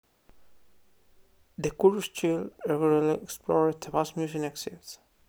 UniDataPro/speech-emotion-recognition at main
euphoric.wav